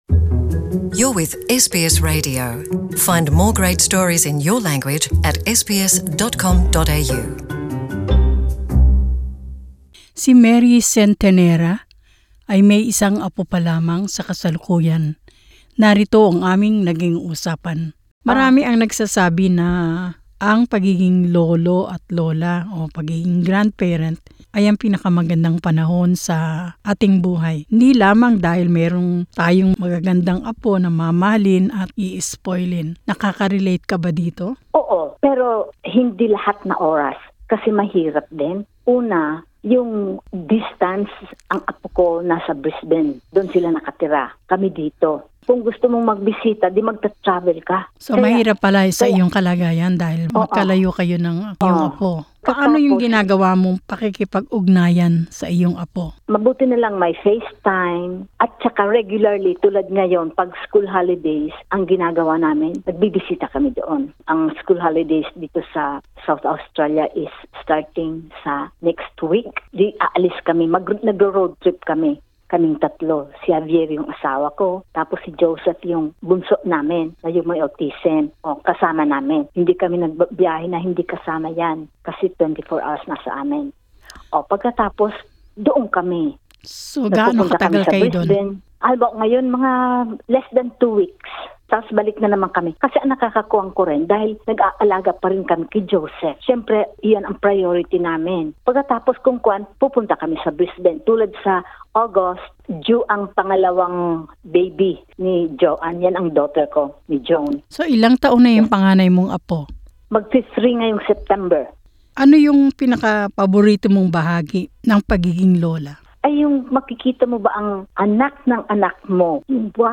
Sinasabing ang pagkakaroon ng apo ay ang pinakamagandang panahon sa buhay ng isang magulang. Inalam natin sa panyam na ito ang mga karanasan ng tatlong mga lola na taga South Australia.